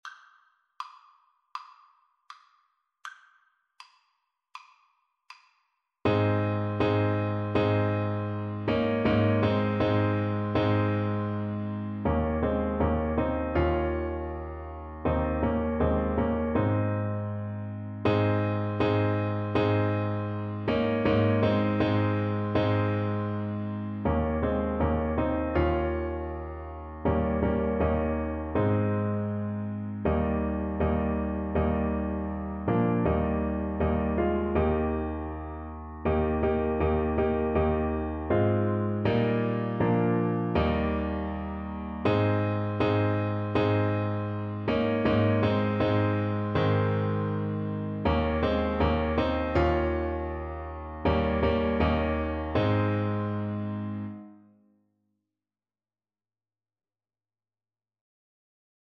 Alto Saxophone
4/4 (View more 4/4 Music)
Andante maestoso =c.80